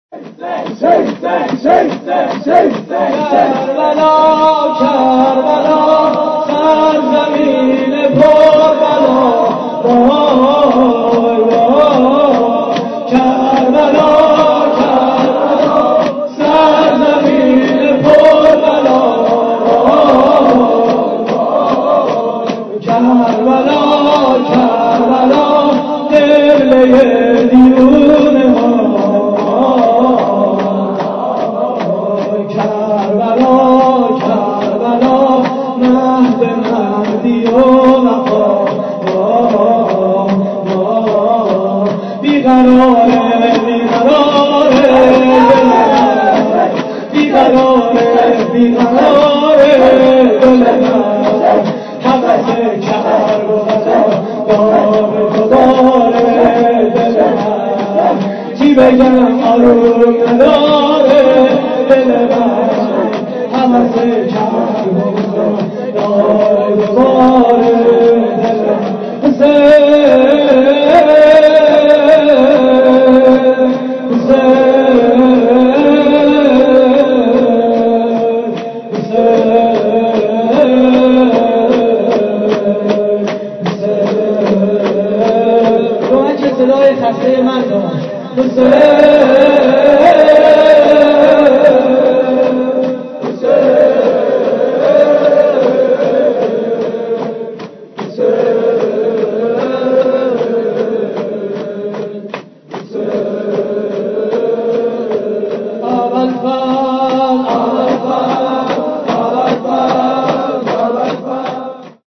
شور: سرزمین پربلا
مراسم عزاداری اربعین حسینی